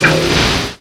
Cri de Blindalys dans Pokémon X et Y.